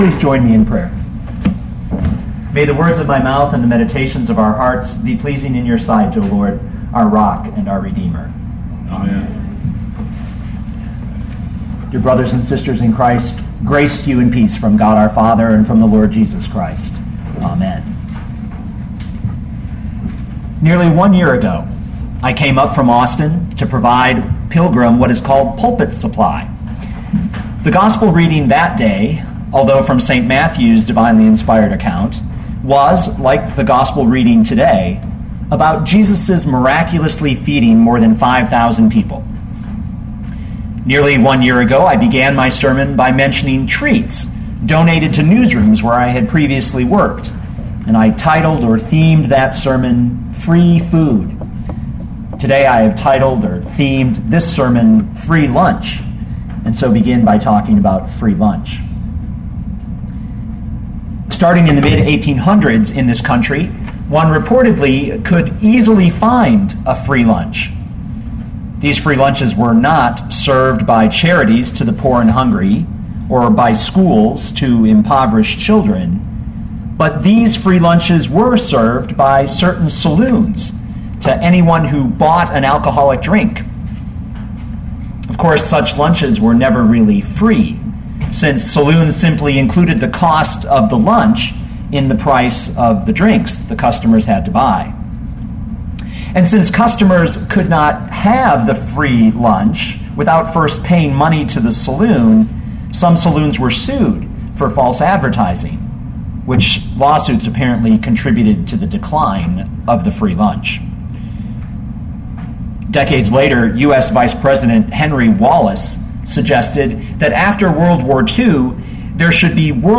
2012 Mark 6:30-44 Listen to the sermon with the player below, or, download the audio.